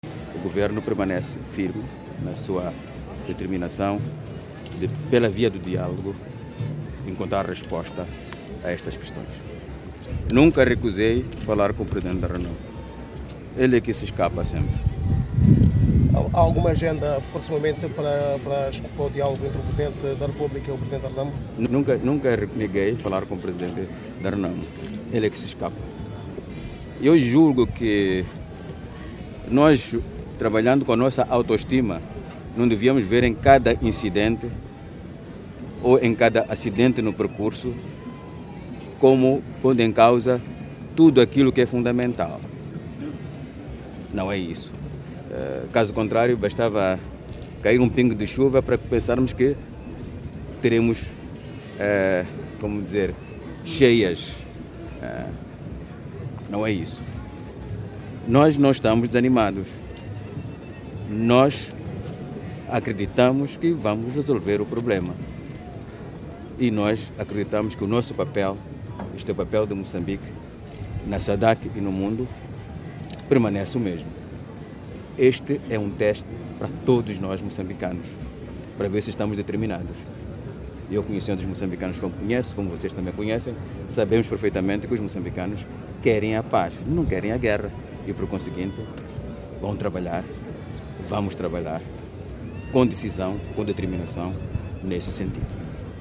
Presidente Guebuza fala sobre as negociacoes com a Renamo - 01:36
Guebuza falava aos jornalistas na Praça dos Heróis, à margem da cerimónia dos 38 anos da independência de Moçambique.